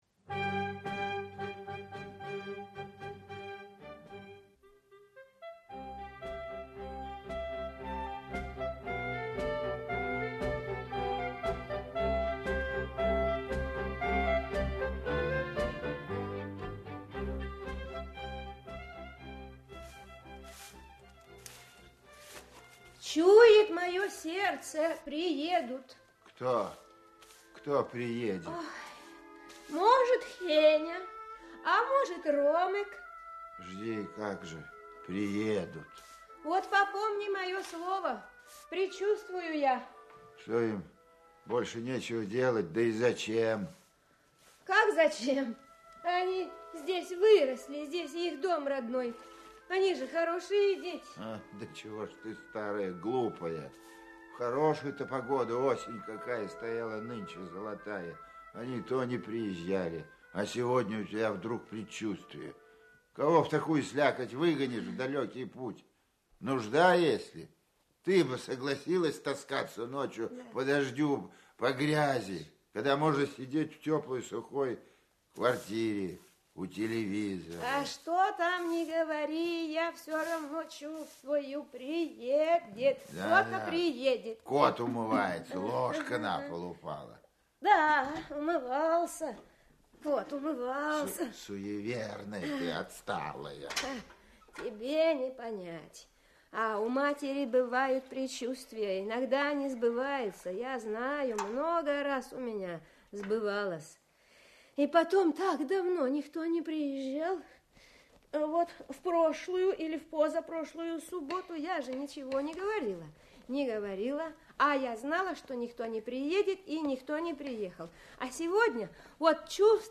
Аудиокнига Ожидание | Библиотека аудиокниг
Aудиокнига Ожидание Автор Йозеф Табор Читает аудиокнигу Актерский коллектив.